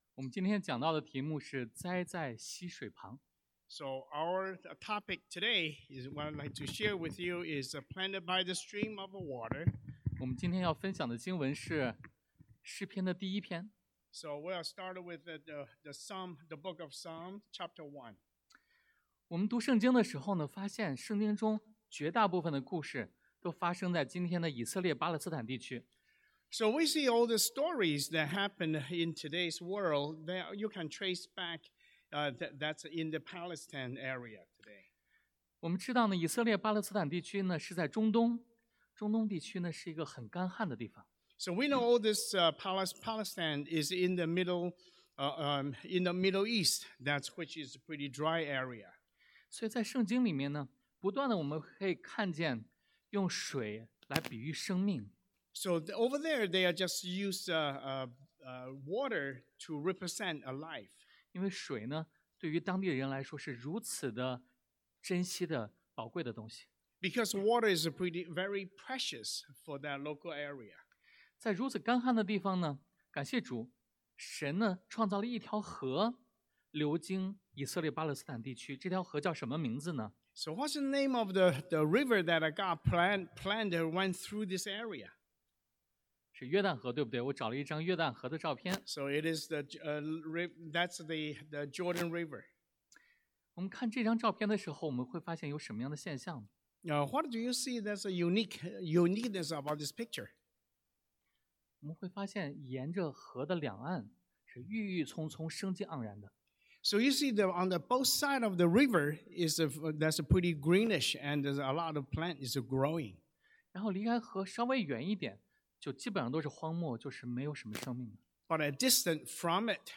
Passage: 诗篇 Psalm 1 Service Type: Sunday AM